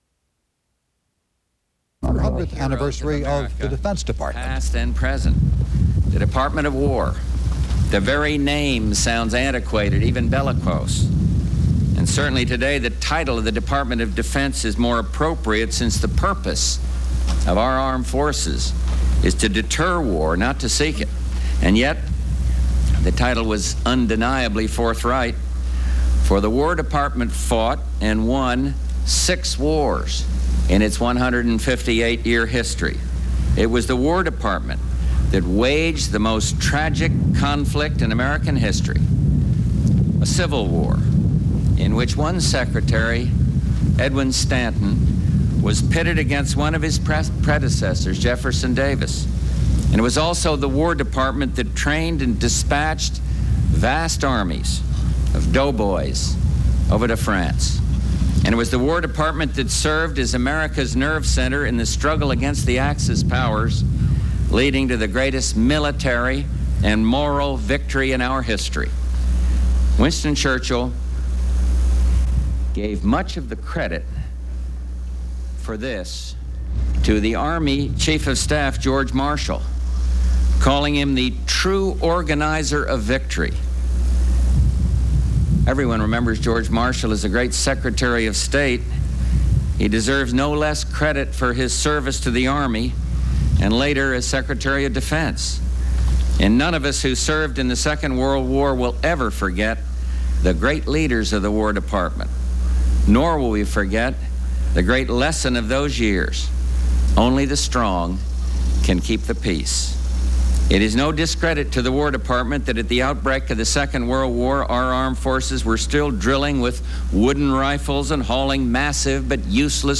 Department of Defense Material Type Sound recordings Language English Extent 00:05:42 Venue Note Broadcast on CNN, August 7, 1989.